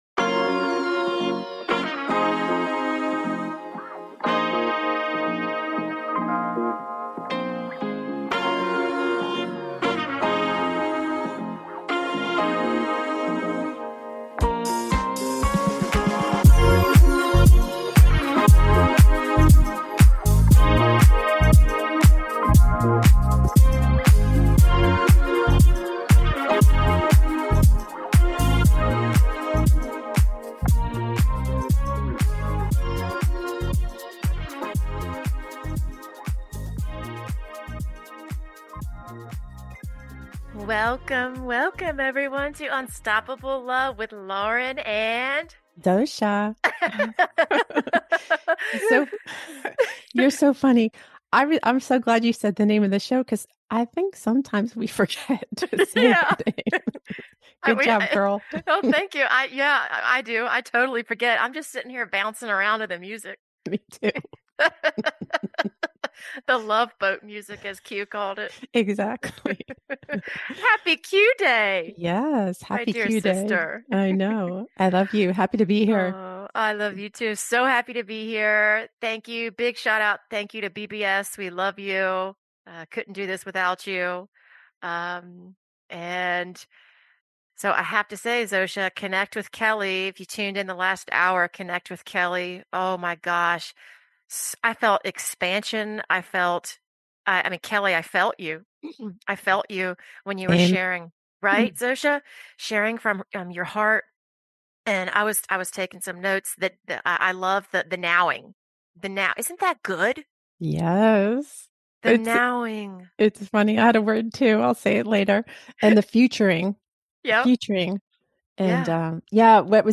Her preferred communication style is spontaneous and organic, while creating an environment of authenticity and peace, so sharing from their hearts is easy and natural for her listeners.